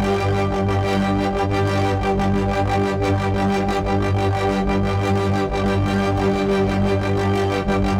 Index of /musicradar/dystopian-drone-samples/Tempo Loops/90bpm
DD_TempoDroneA_90-G.wav